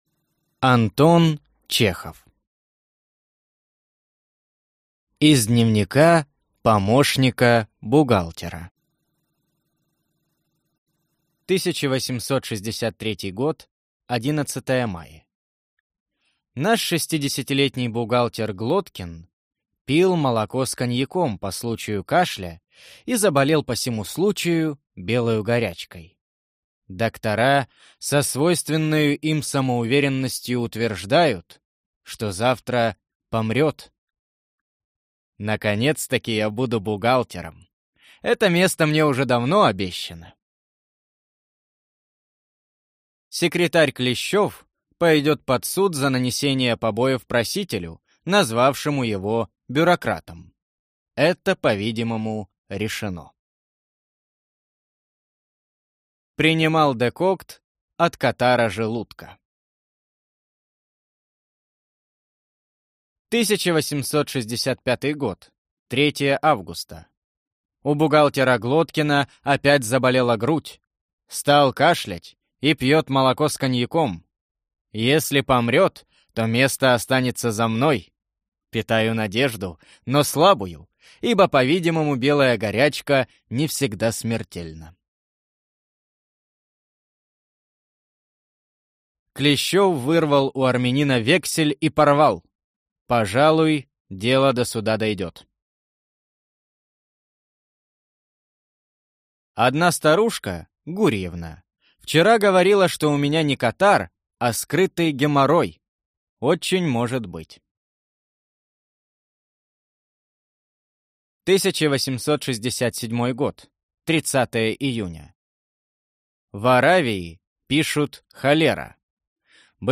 Аудиокнига 33 лучших юмористических рассказа | Библиотека аудиокниг